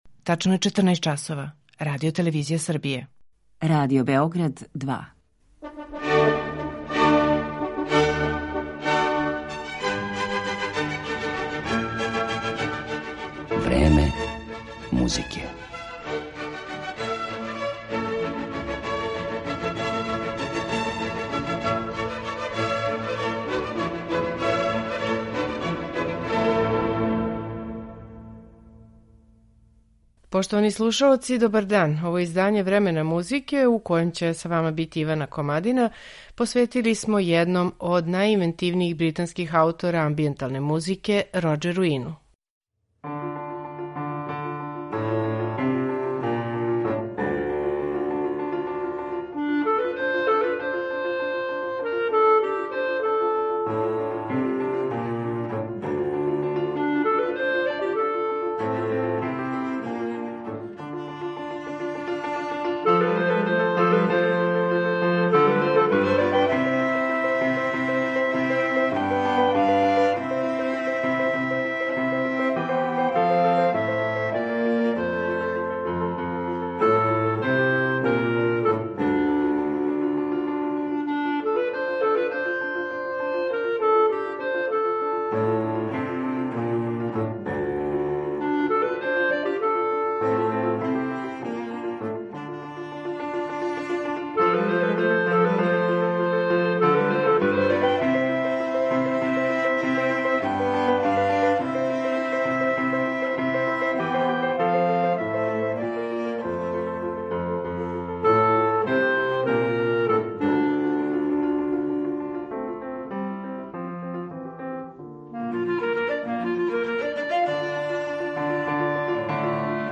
амбијенталне музике